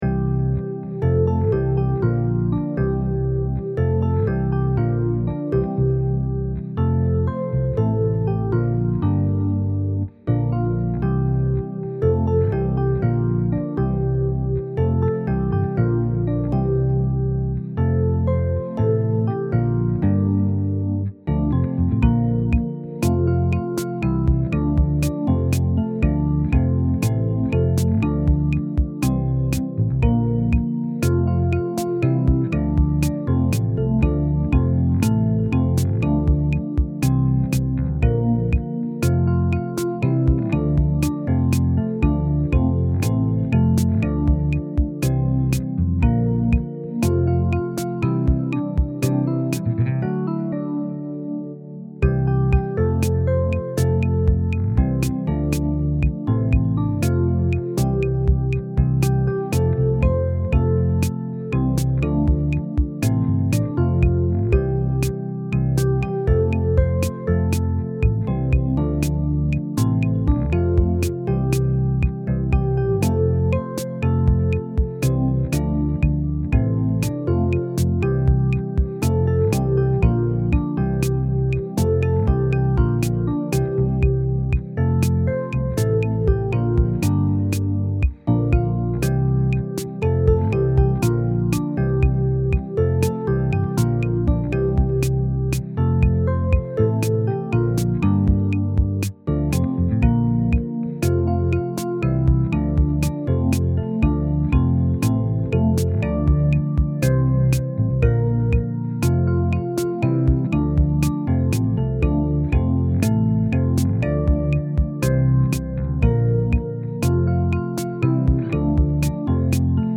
ProgRockBallad
모든 공연은 신시사이저 톤에서 선택되었습니다.